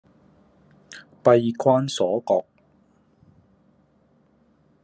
Голоса - Гонконгский 14